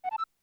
msg_finish.wav